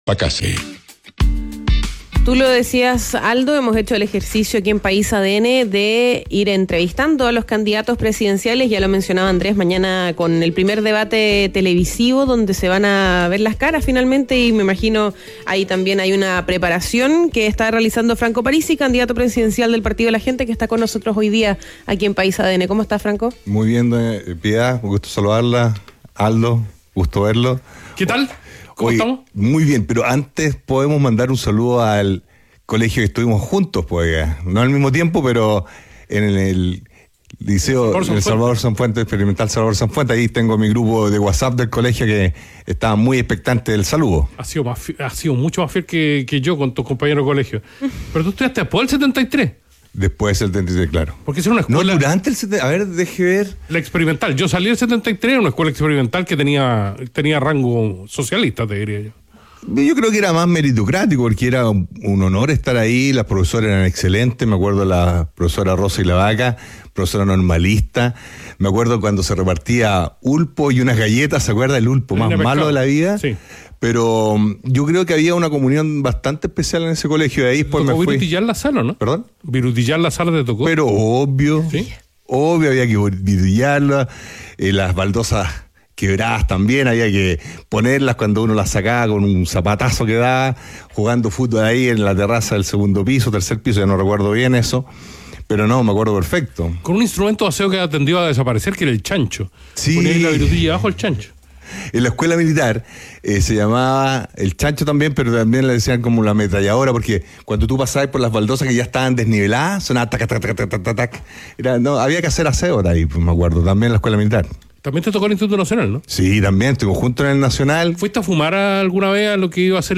País ADN - Entrevista al candidato presidencial Franco Parisi